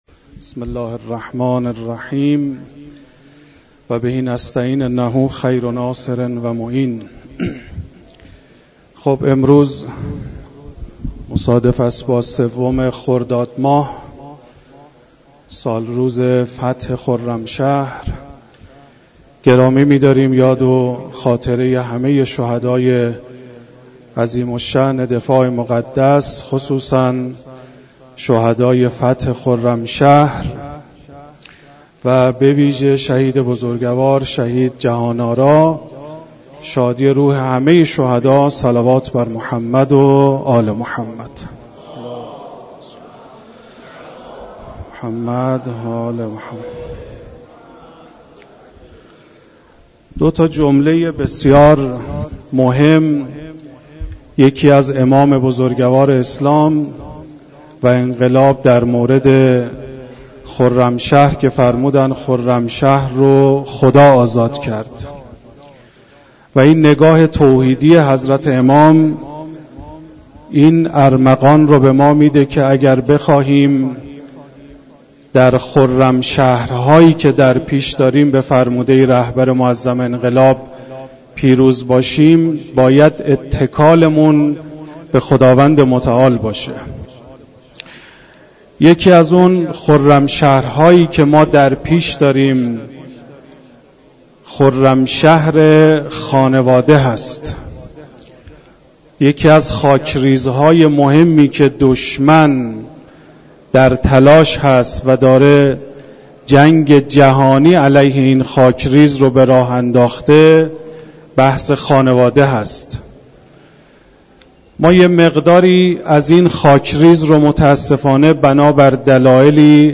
بیان مباحث مربوط به خانواده و ازدواج در کلام مدرس حوزه و دانشگاه در مسجد دانشگاه کاشان